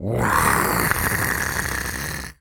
lizard_taunt_hiss_02.wav